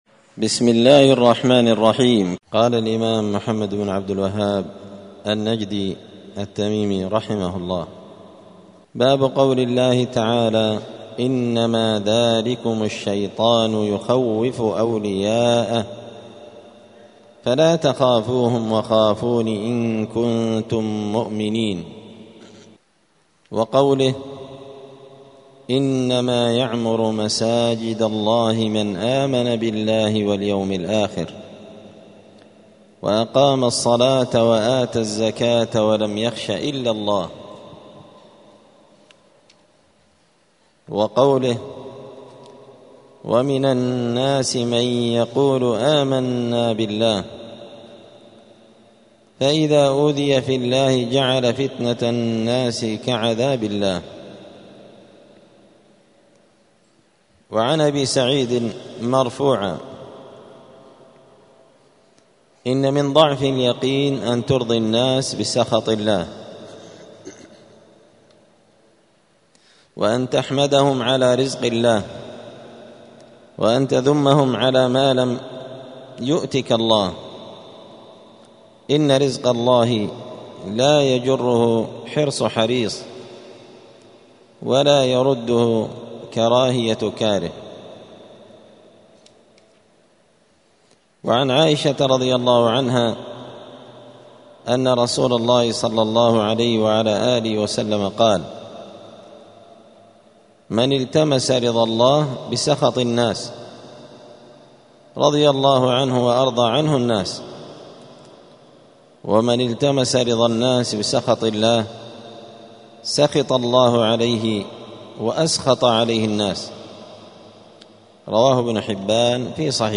دار الحديث السلفية بمسجد الفرقان قشن المهرة اليمن
*الدرس التاسع والثمانون (89) {باب قول الله تعالى إنما ذلكم الشيطان يخوف أولياءه}*